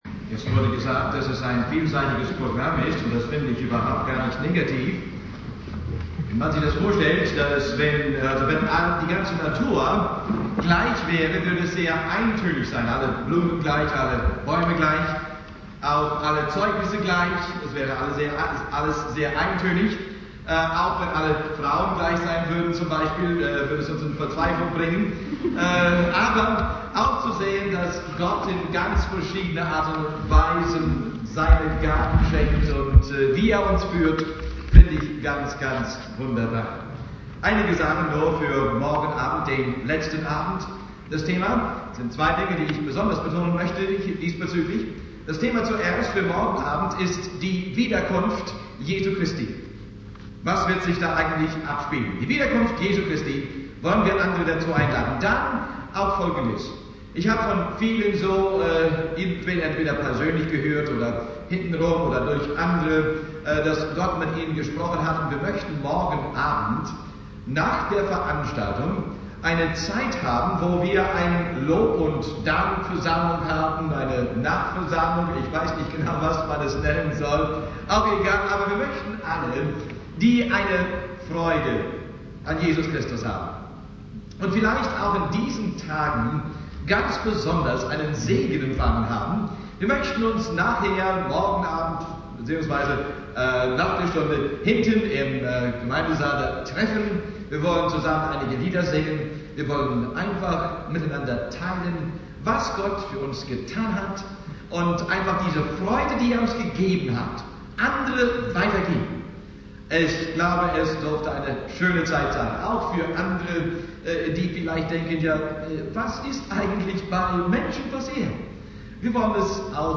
9. Abend der Evangeliumswoche Inhalt der Predigt: weitere Bibelstelle: Römer 2,5 1.